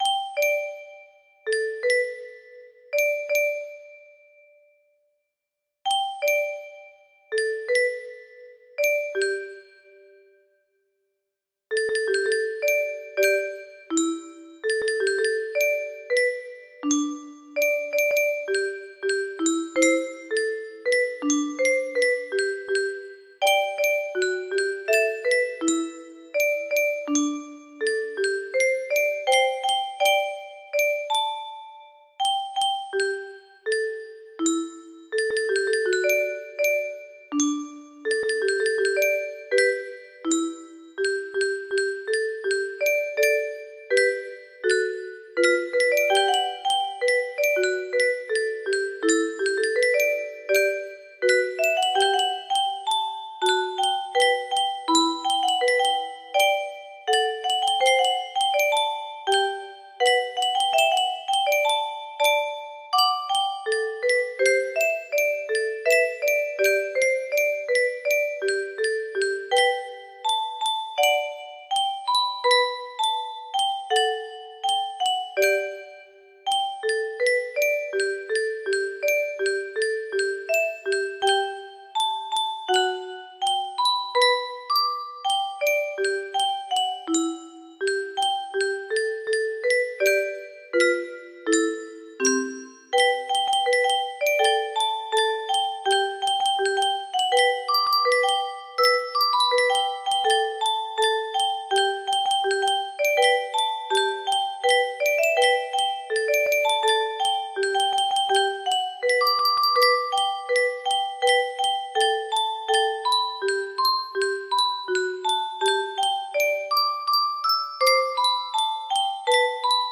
lightlight music box melody